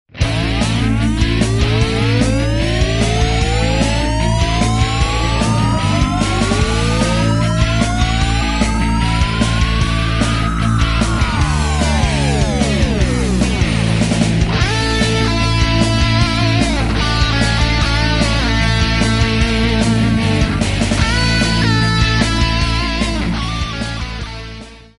principalmente em seus solos de guitarra.